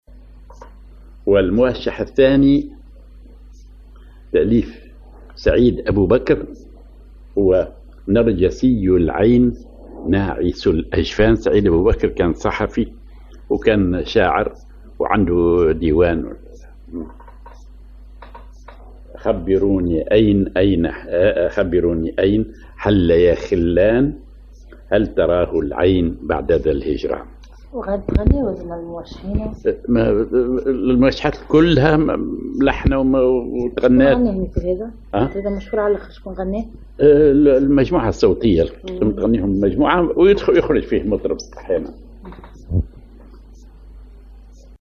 Maqam ar محير
Rhythm ar نشوان
genre موشح